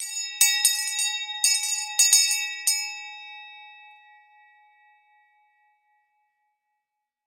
Звуки колокольчика
Звук зовущего слуг колокольчика